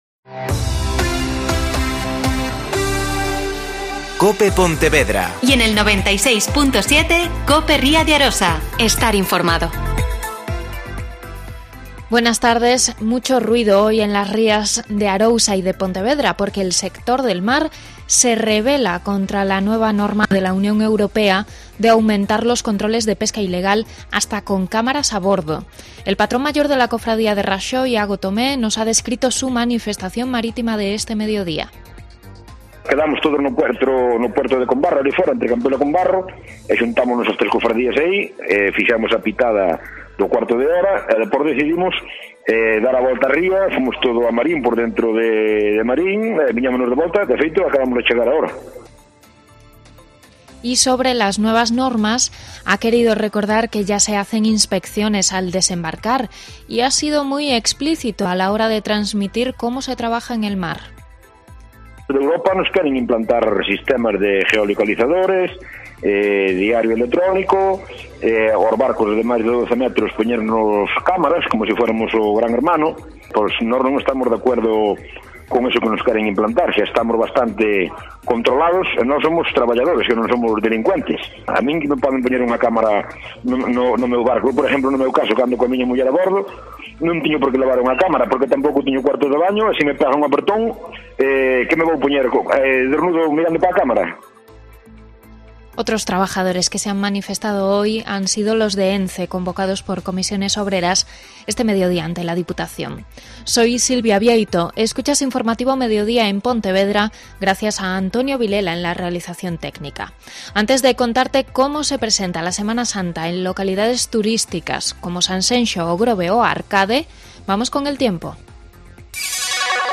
Mediodia COPE Pontevedra y COPE Ría de Arousa (Informativo 14:20h)